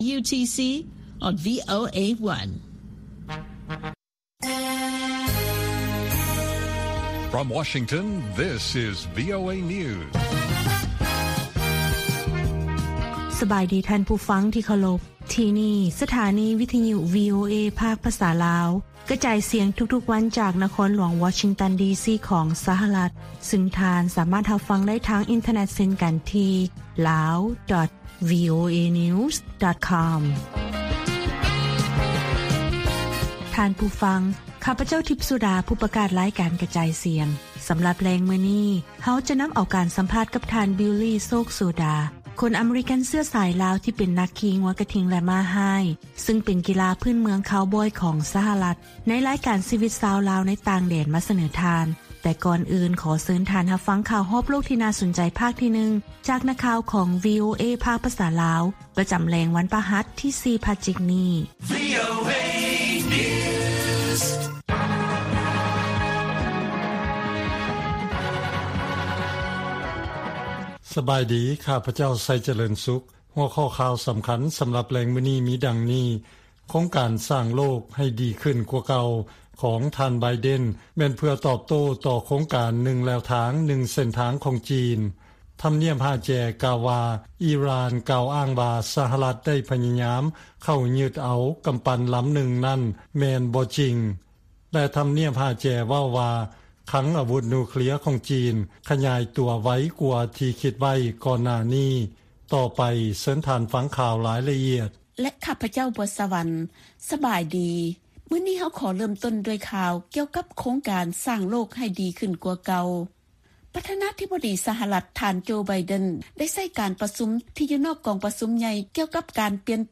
ລາຍການກະຈາຍສຽງຂອງວີໂອເອ ລາວ: ຄົນພິການໃນລາວໄດ້ຮັບບູລິມະສິດ ໃນການສັກວັກຊີນກັນໂຄວິດ ແລະໄດ້ ຮັບຄວາມສະດວກພິເສດຈາກແພດໝໍ ແລະເຈົ້າໜ້າທີ່